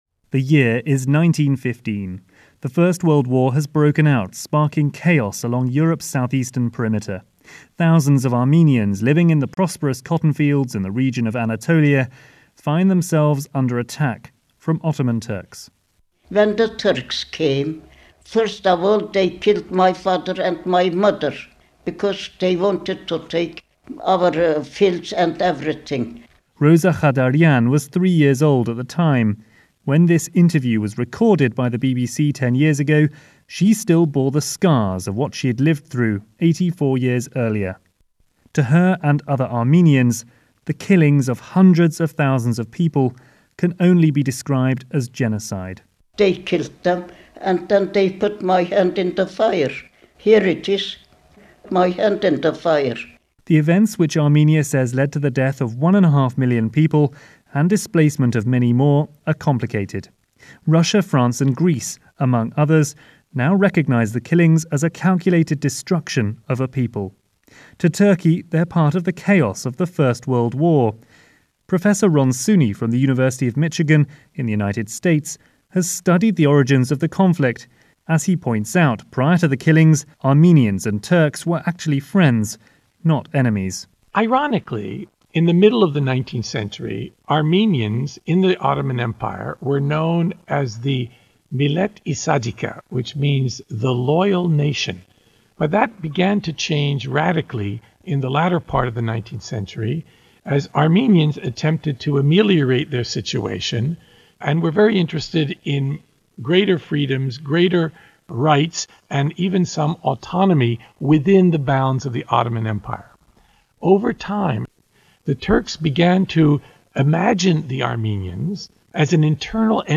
If you missed this report – from the BBC World Service program Newsday on April 24th – here is Armenia: Voices of 1915 and interviews and background.
BBC-Newsday-Armenia-Voices-1915.mp3